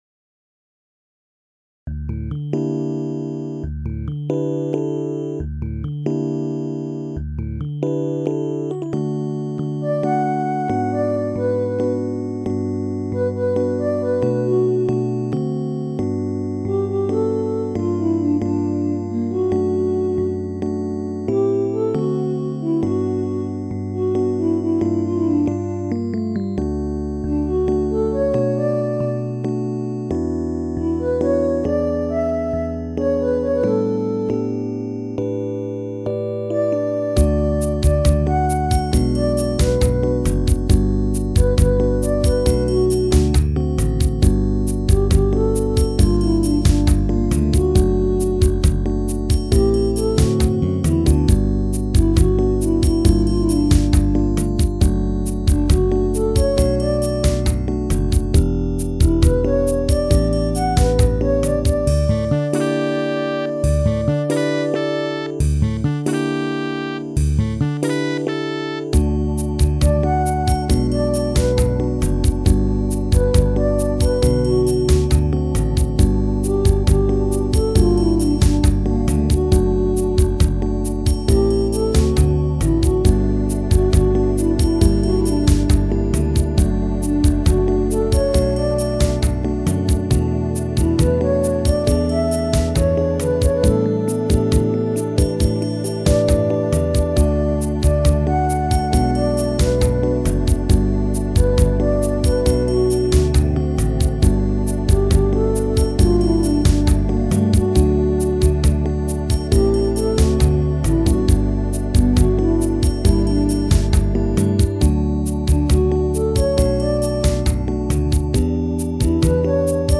イントロのピアノのツカミは Key Of D でしか出せない。